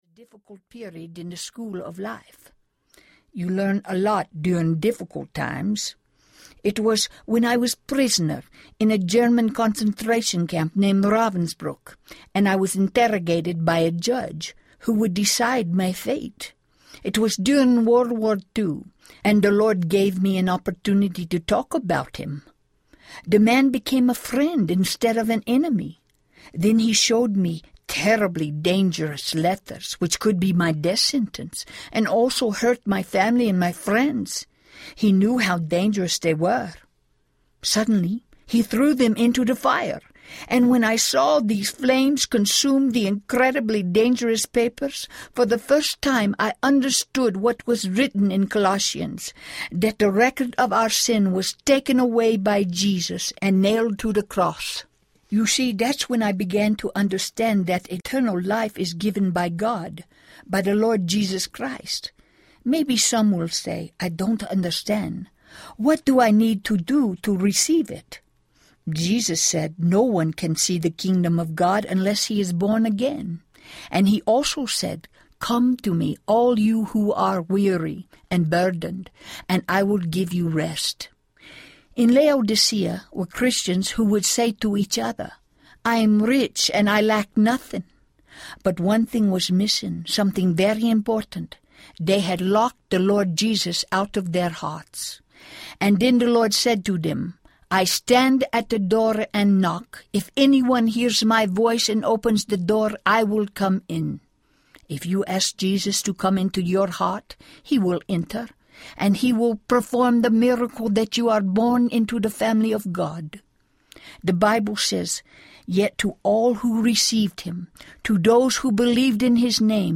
I Stand at the Door and Knock Audiobook
Narrator
4.05 Hrs. – Unabridged